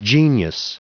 .noun,.plural.geniuses.or.genii.(pronounced 'gene e i')